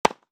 Tennis Volley 01.m4a